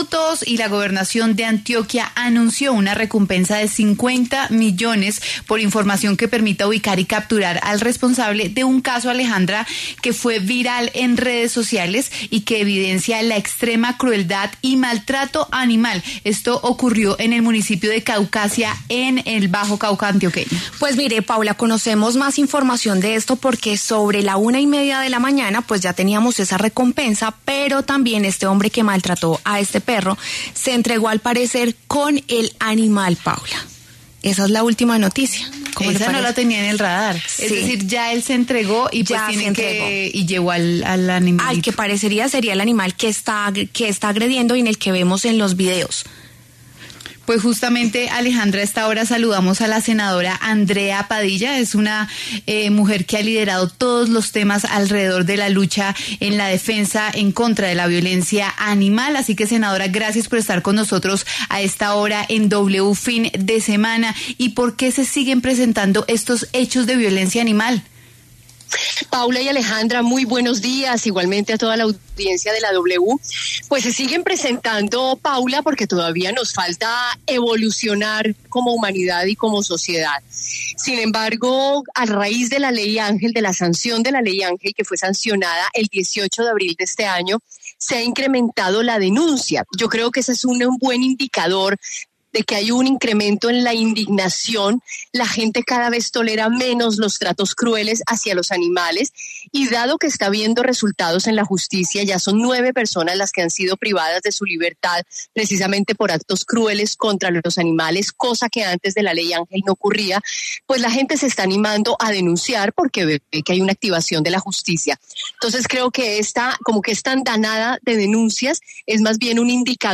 La senadora Andrea Padilla se refirió en W Fin de Semana a la millonaria recompensa por información sobre el hombre que golpeó a un perro en el Bajo Cauca antioqueño.